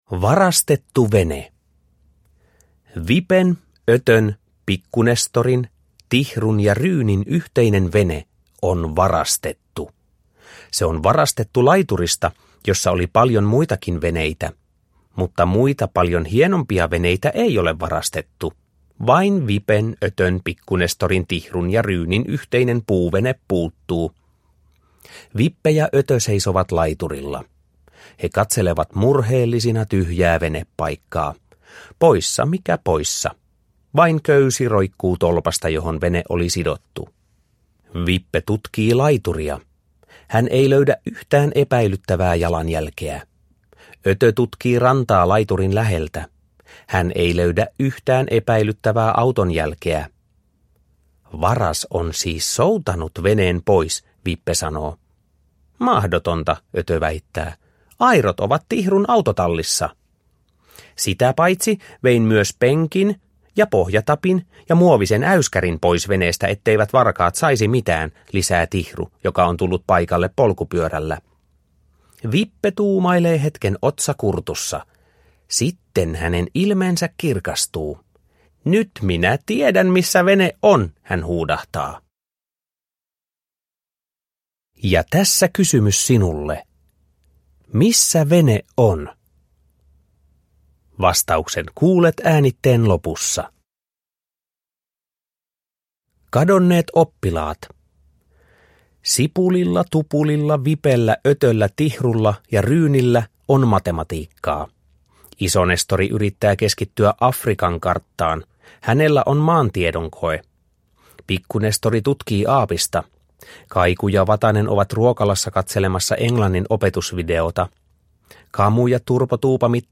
Pusuja ja pulmia – Ljudbok – Laddas ner